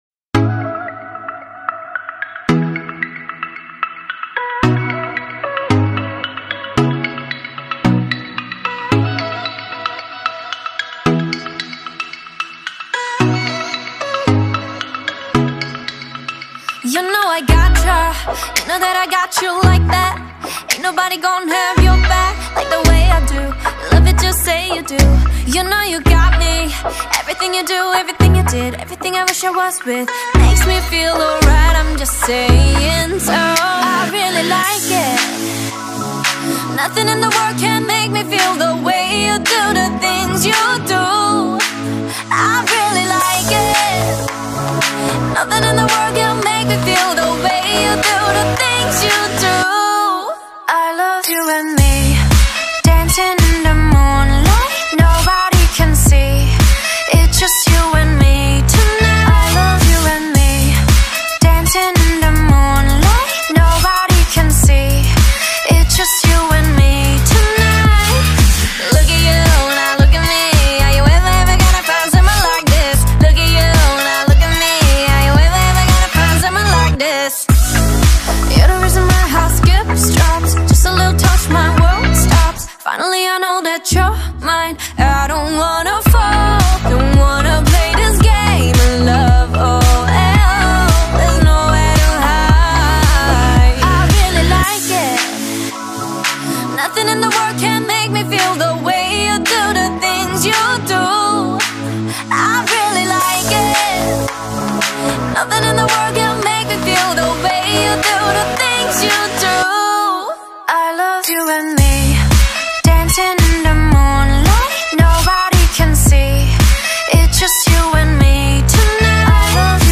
Новая корейская музыка — K-Pop